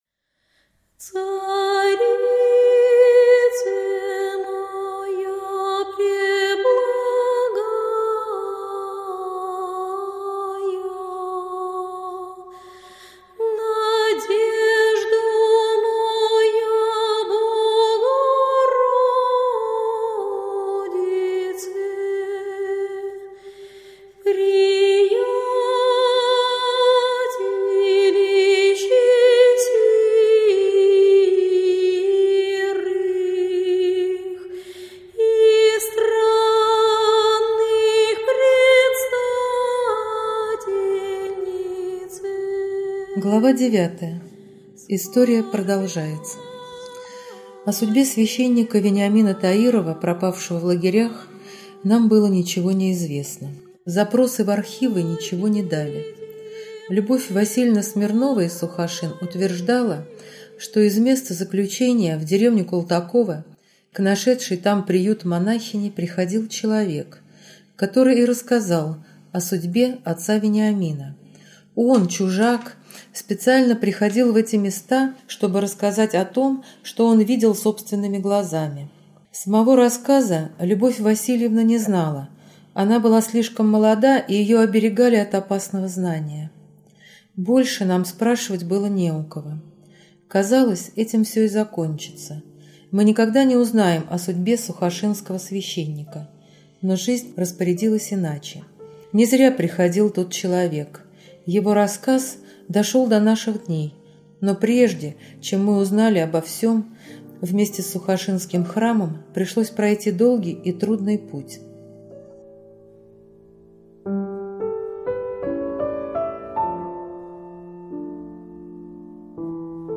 2009 Жанр: Аудиокнига Читает